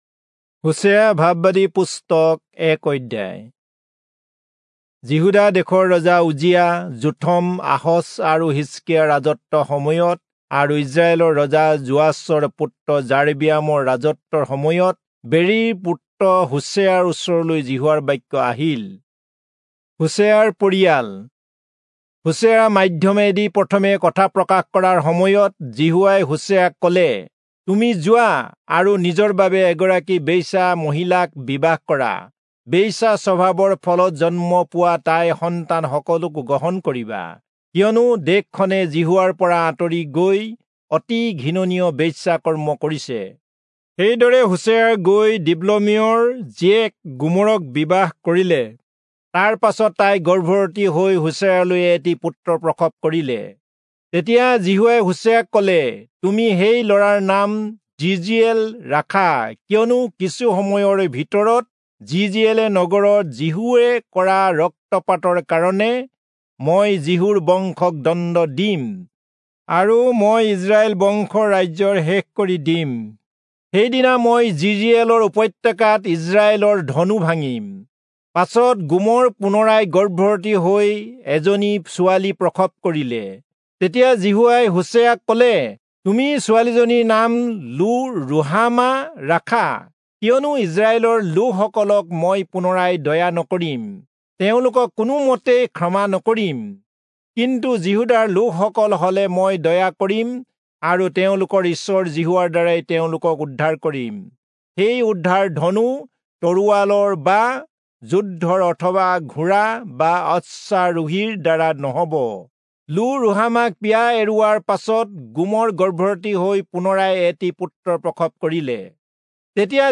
Assamese Audio Bible - Hosea 10 in Bnv bible version